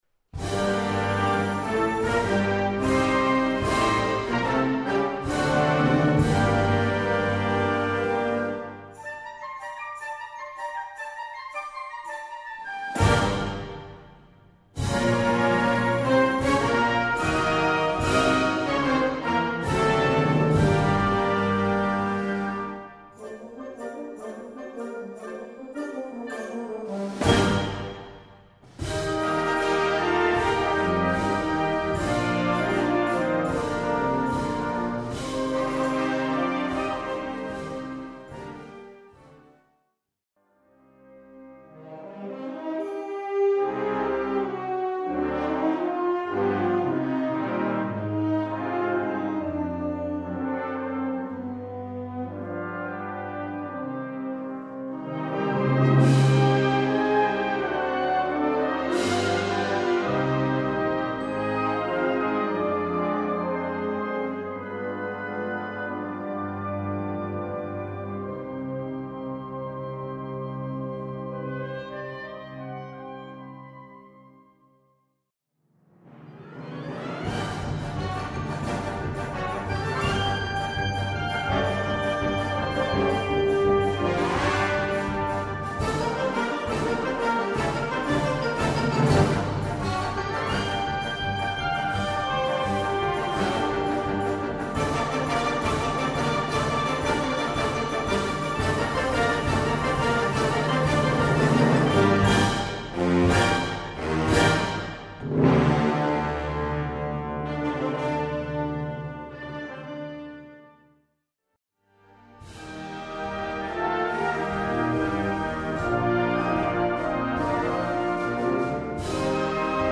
Gattung: Balkan-Rhapsodie
Besetzung: Blasorchester